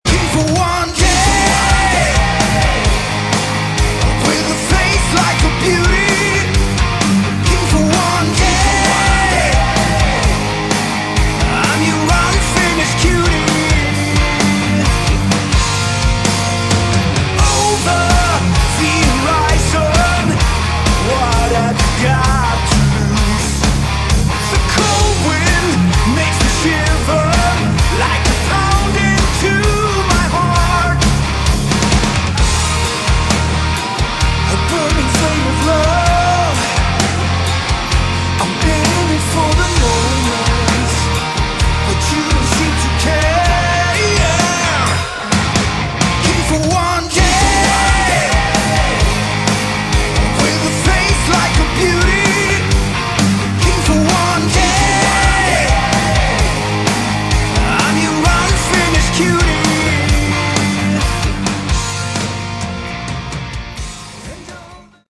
Category: Hard Rock
lead vocals
guitars and vocals
bass and vocals
drums, percussion, and vocals
Harmonies,hooks, & catchy songs/lyrics with minimum filler.